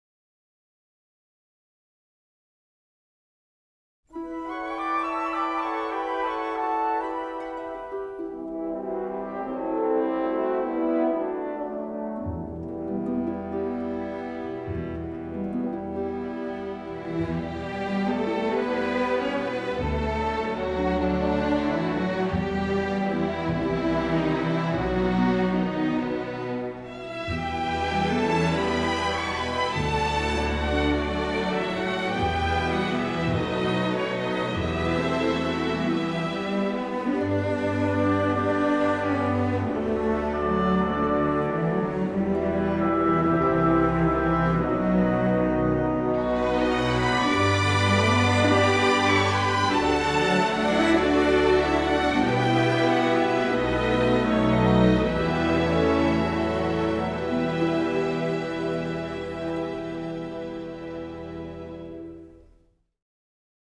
125_narodnaya_melodiya_zelenye_rukava.mp3